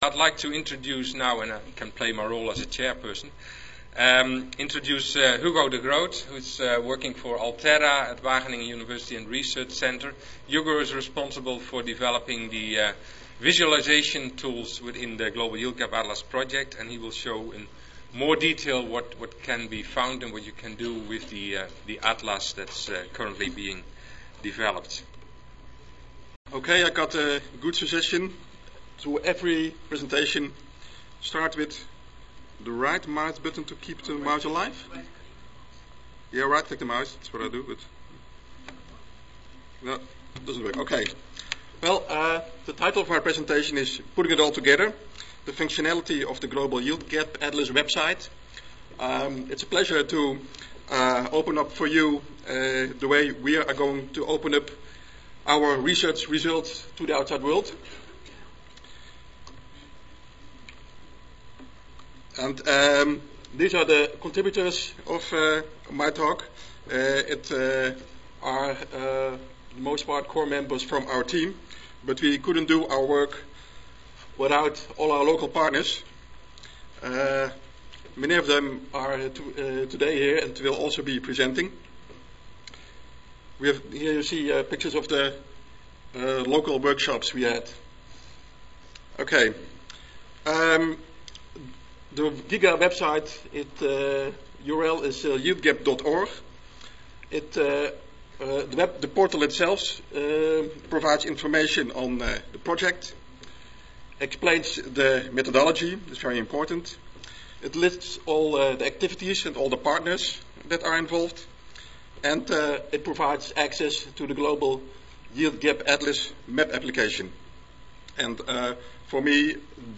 Session: Symposium--Crop Yield Gap Assessment for Global Food Security (ASA, CSSA and SSSA International Annual Meetings)
Recorded Presentation